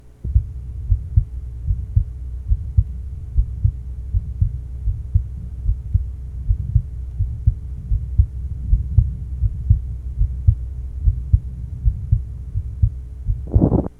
Date Unknown Type Systolic Abnormality Innocent murmur Innocent murmur To listen, click on the link below.